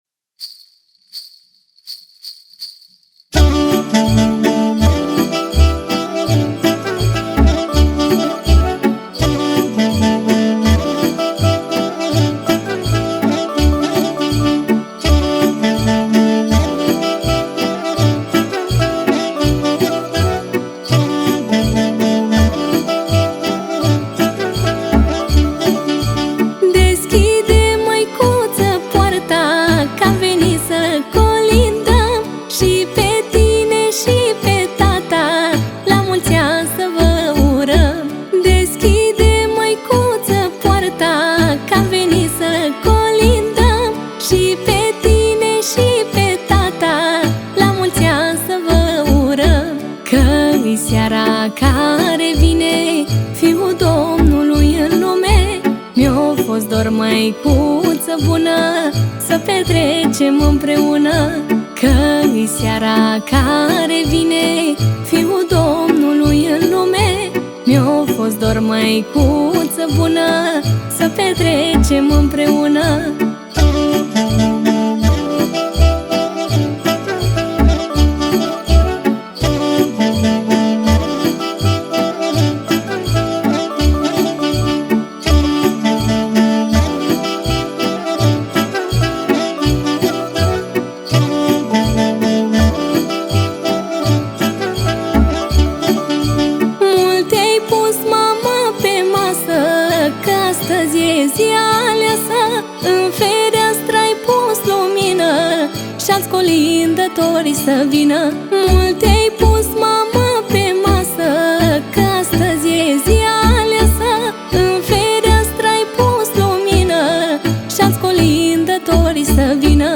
Colinde de Craciun